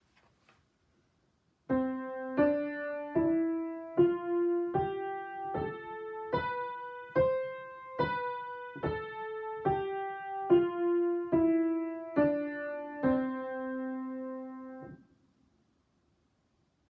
軽快なオクターブ：長音階（メジャースケール）
ハ長調におけるメジャースケールは「ド」の音を基準にオクターブ全てを白鍵で辿ります。
明るく安定した、爽やかな響きですよね。
Scale_Cmajor1.m4a